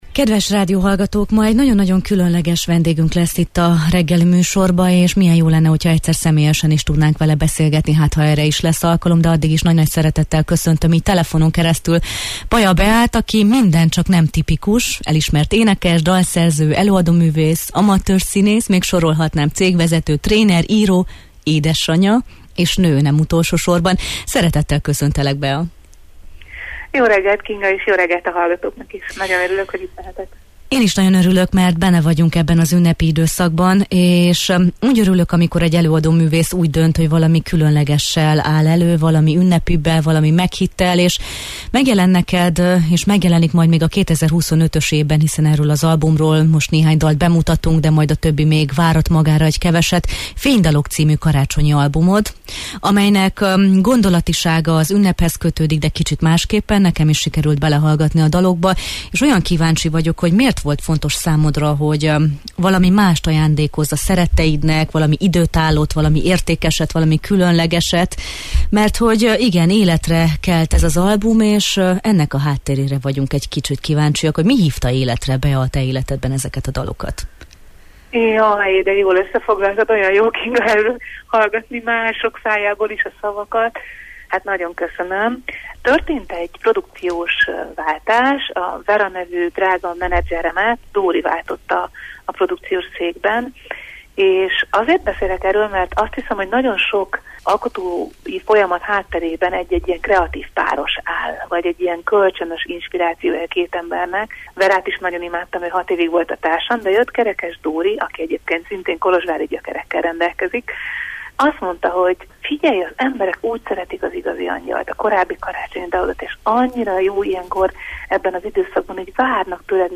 Az énekesnő a Jó reggelt, Erdély!-ben mesélt az új album dalairól, az egész zenei albumot átívelő nem vagy egyedül gondolatról, az alkotás szépségéről, az ünnepről: